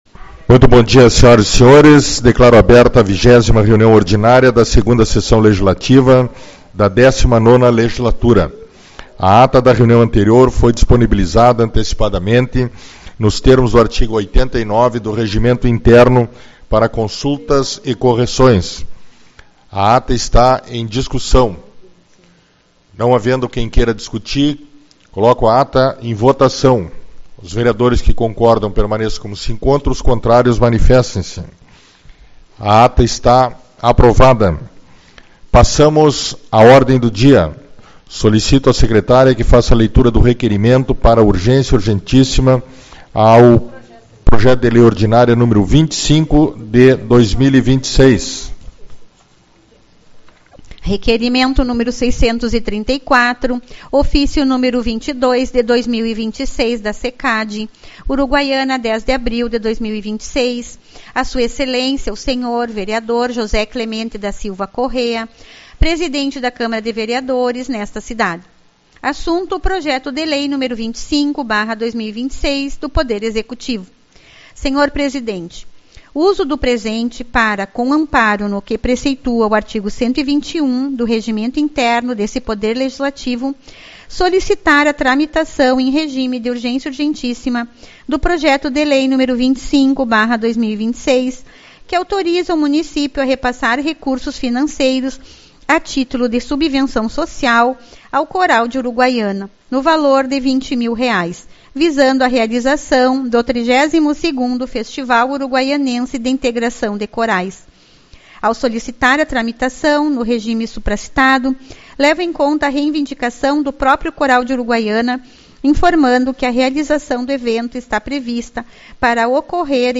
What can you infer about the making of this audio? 14/04 - Reunião Ordinária